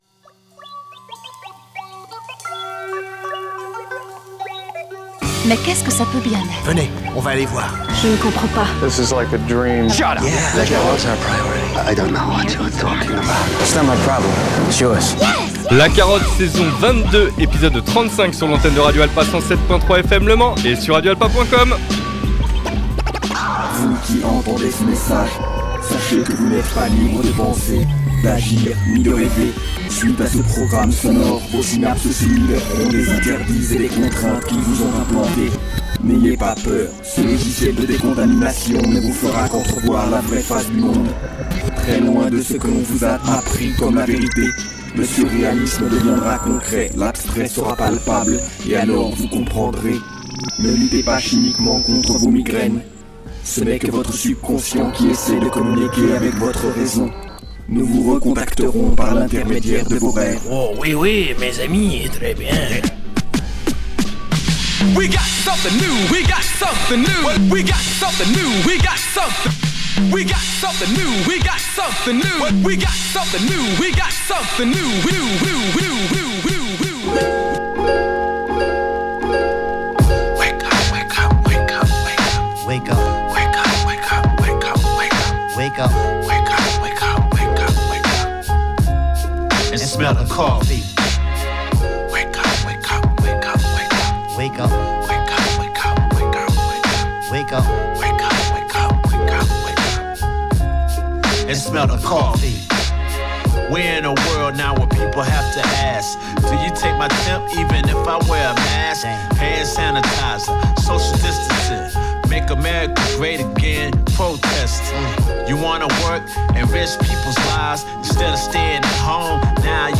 News #9 // Florilège de nouveautés pour les oreilles curieuses avec un panel qui va du boom bap, au jazz, au trip-hop voir l'indie rock.
UNDERGROUND HIP HOP